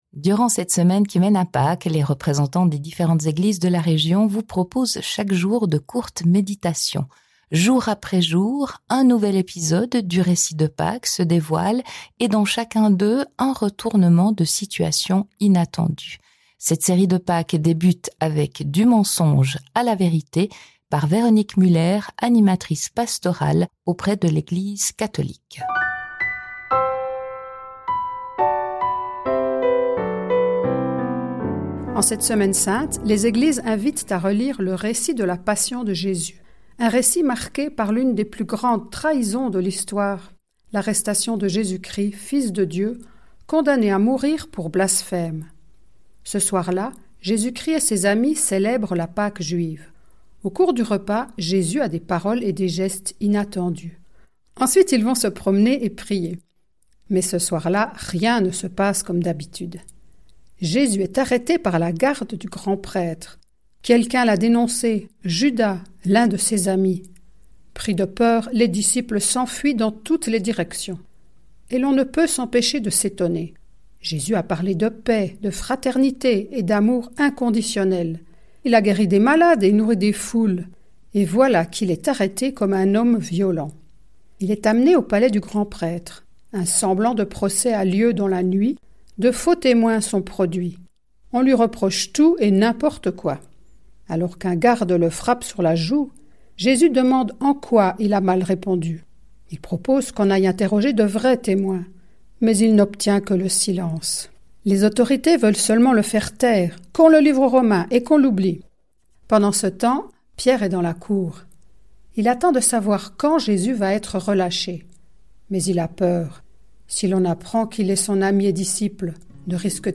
Durant cette semaine qui mène à Pâques, les représentants des différentes Eglises de la région vous proposent, chaque jour, de courtes méditations.